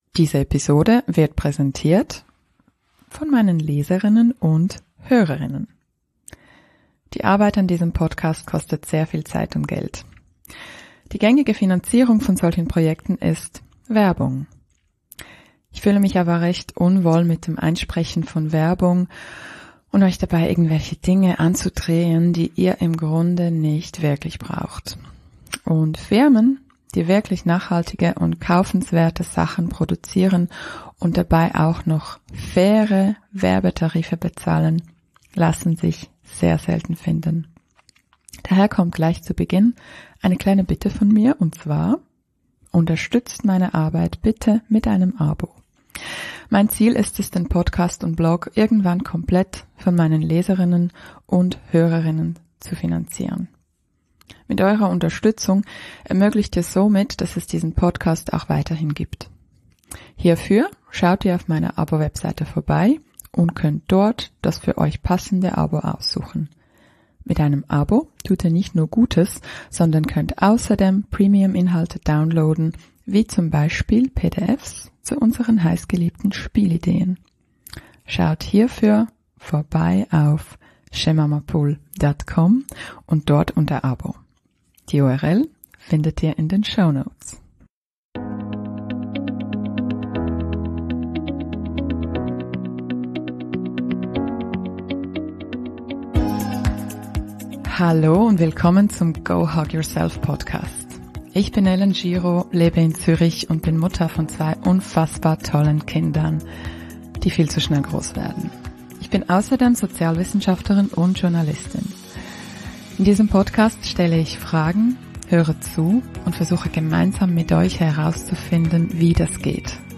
Diese Episode ist somit eine Hörempfehlung für alle Schwiegereltern, für Paare die sich gerade trennen und vor allem: Für alle Noch- und Frischverliebte. Transkript des Interviews